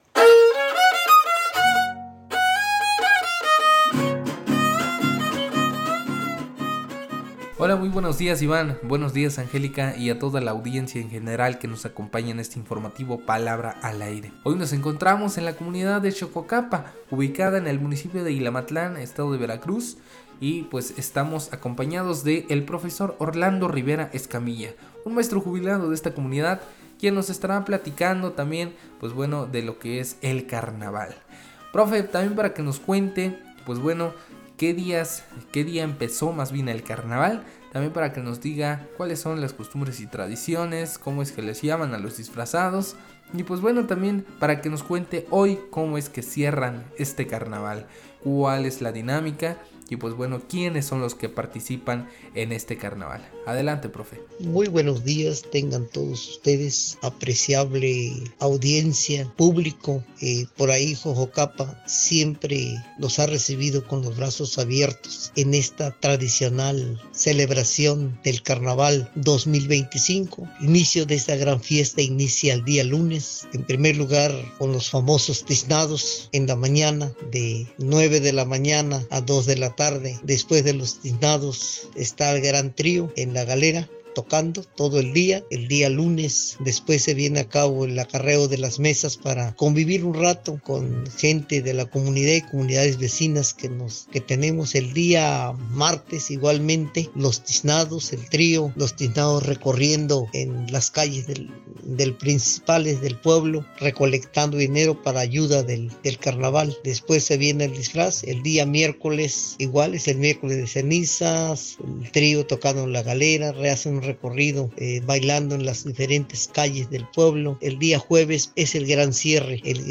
Cierre de carnaval en Xoxocapa, Ilamatlán, Veracruz.